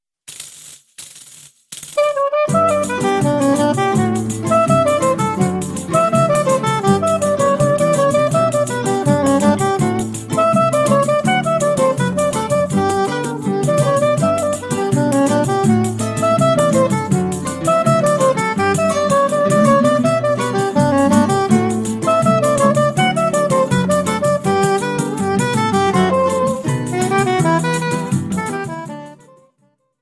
accompanied by a Choro Ensemble.
soprano saxophone